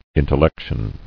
[in·tel·lec·tion]